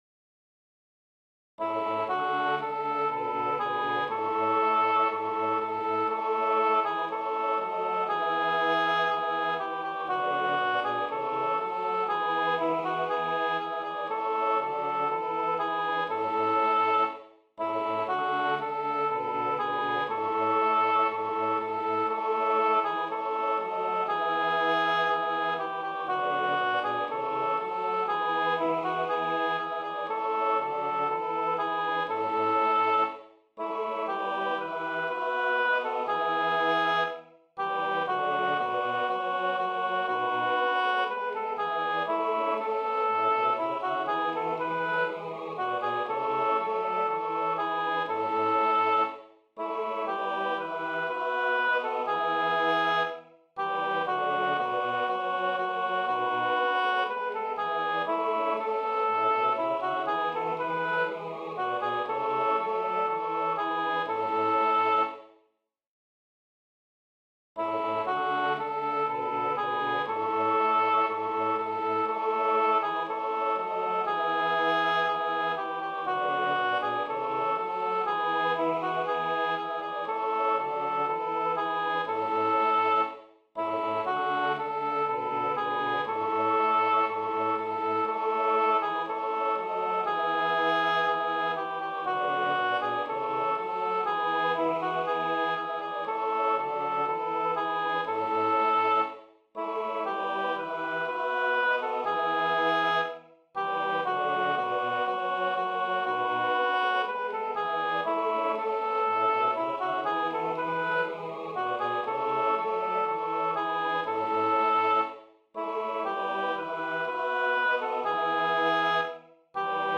Sopran2/Alt1